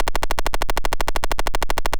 disarm_multi_a.wav